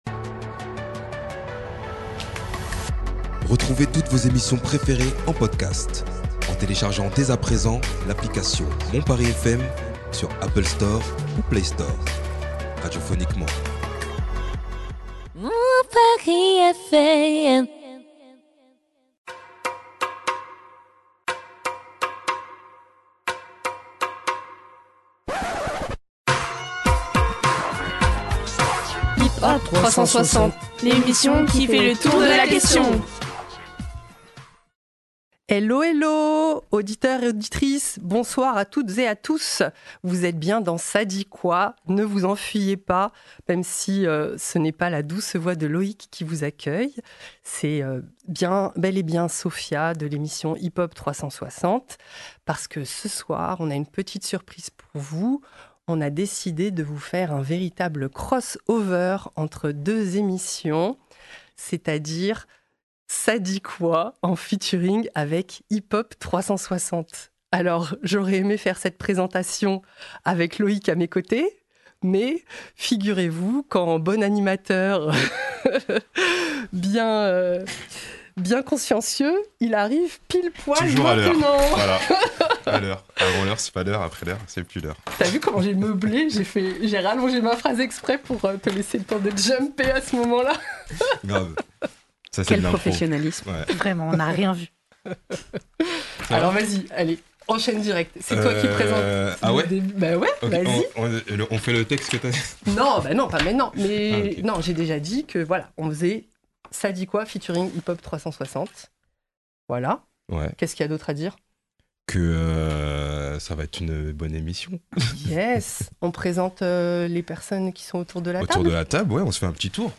Sujet 3 : Mort d'un militant d'extrême droite à Lyon Débat : Engagement des artistes hip-hop